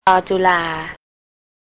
ลอ-จุ-ลา
lor ju-lar